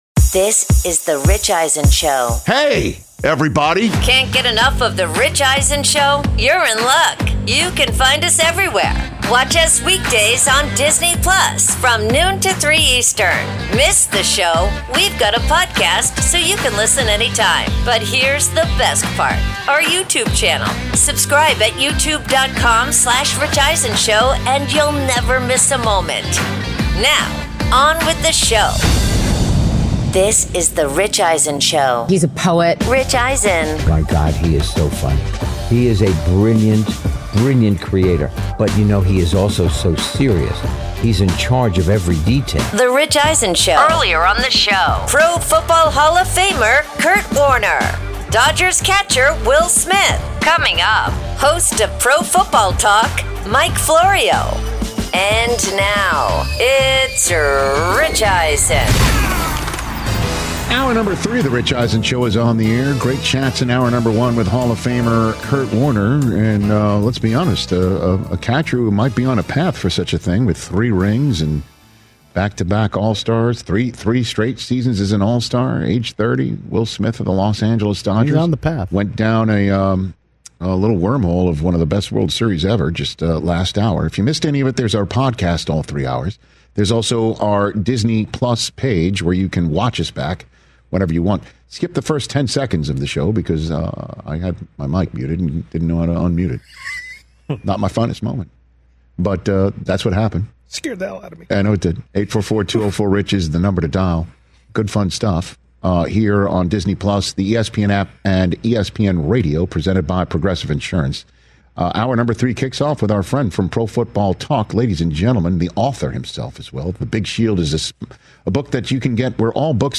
Hour 3: Pro Football Talk’s Mike Florio and O’Shea Jackson Jr. In-Studio